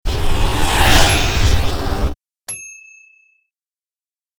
Jet Or Plane Flies By Sound Effect
A jet flies by. This could be used as background noise for an airport scene as well as various other media uses.
JetFliesBy.mp3